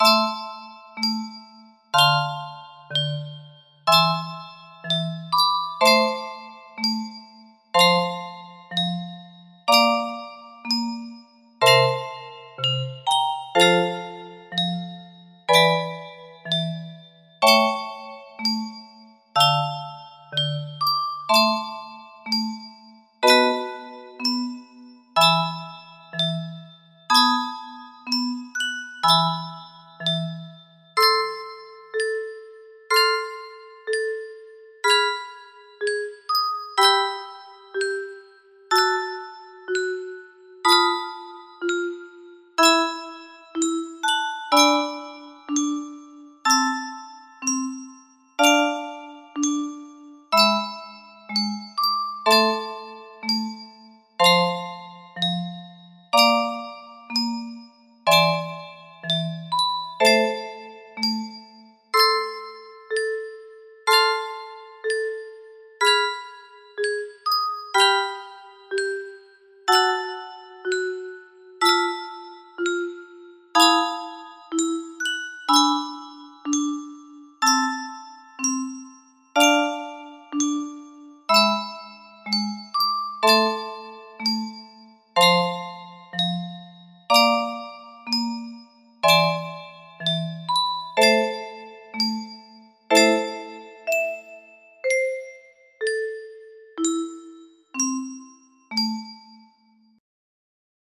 Chopin prelude op.28 no.20 music box melody
Full range 60
this prelude has been transposed into a minor. the composition needs to be raised one octave up due to the restriction of the bass range. also since there is no sustain option, every base note is played twice.